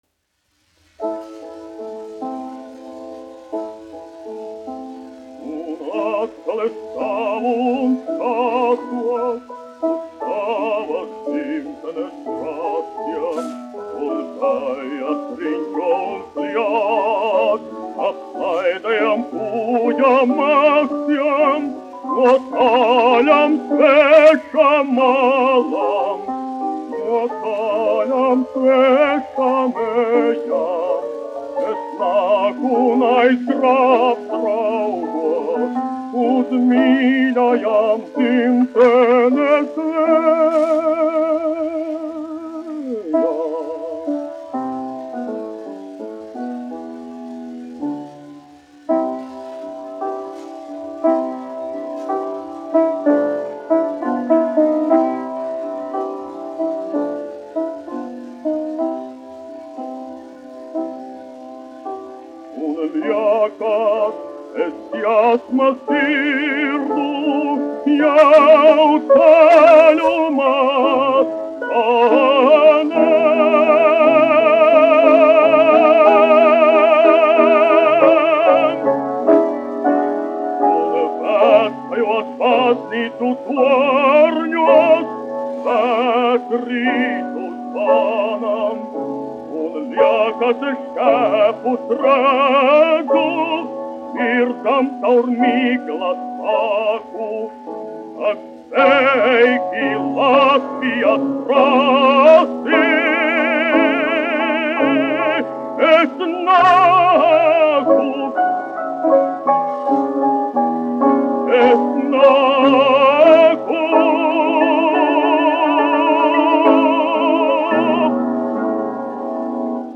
1 skpl. : analogs, 78 apgr/min, mono ; 25 cm
Dziesmas (zema balss) ar klavierēm
Latvijas vēsturiskie šellaka skaņuplašu ieraksti (Kolekcija)